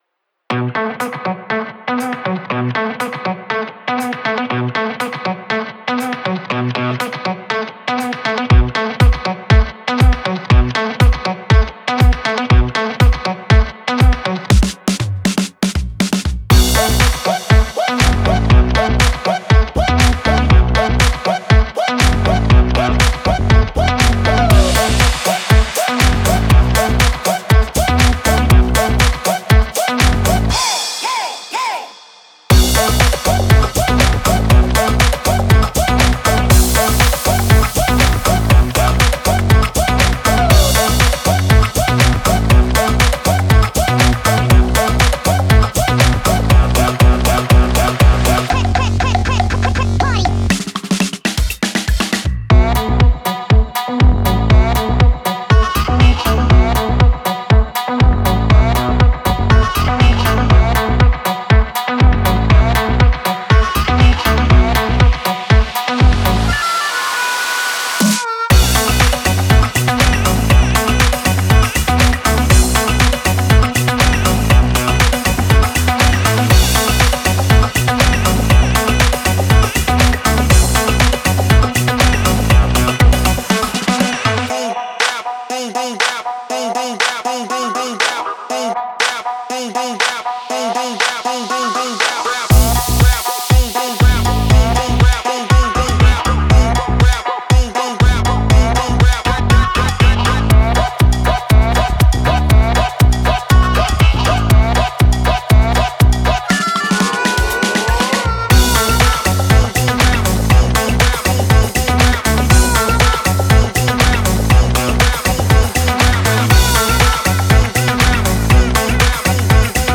Energetic, and cinematic track for deeper emotional moments.